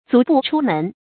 足不出門 注音： ㄗㄨˊ ㄅㄨˋ ㄔㄨ ㄇㄣˊ 讀音讀法： 意思解釋： 同「足不出戶」。